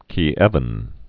(kē-ĕvən)